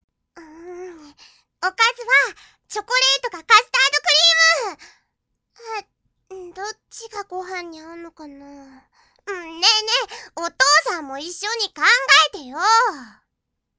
１３歳／女
■　Voice　■